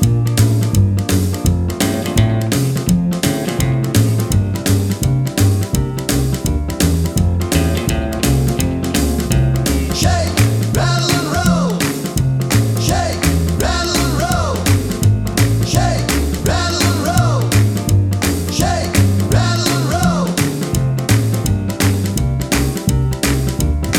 no Backing Vocals Rock 'n' Roll 2:29 Buy £1.50